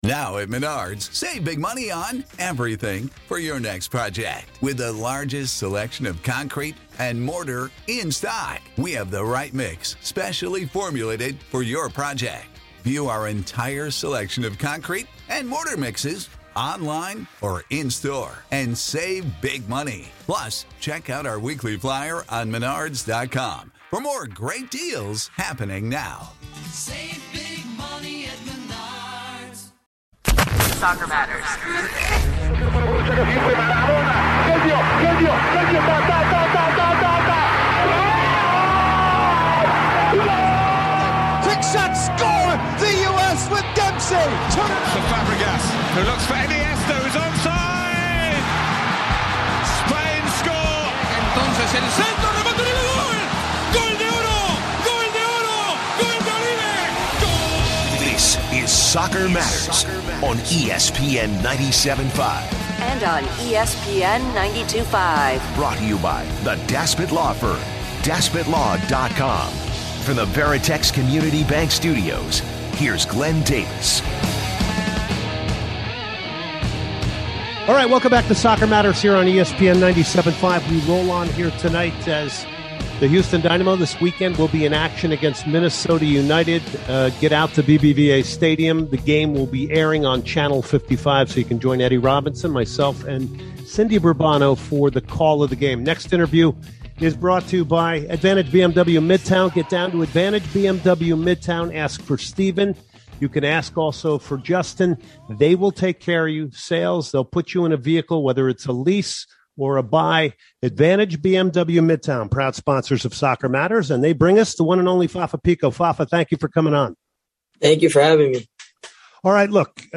He talks to Dynamo Forward Fafa Picault, Dynamo Attacker Griffin Dorsey and Fox Sports Soccer Analyst Warren Barton.